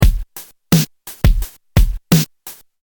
Smooth 86 BPM Downtempo Drum Loop
Smooth-86-BPM-downtempo-drum-loop.mp3